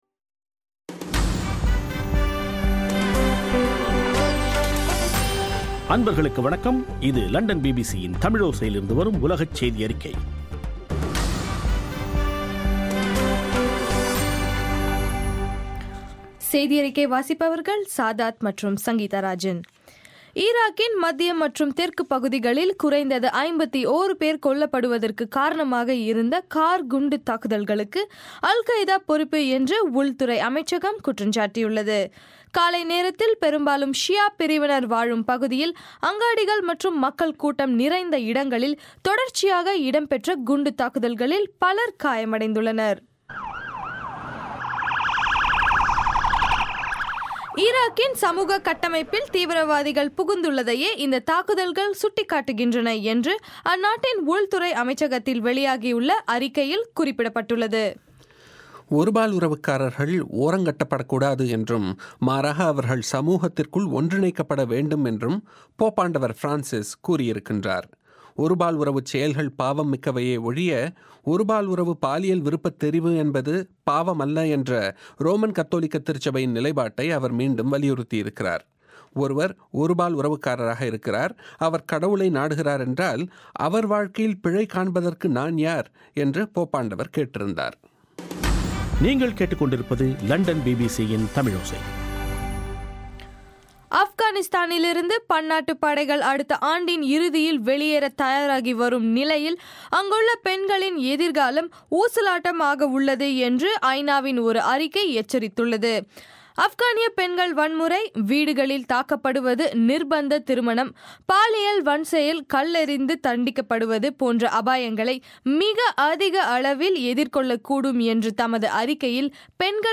சக்தி பண்பலை வானொலியில் ஒலிபரப்பப்பட்டது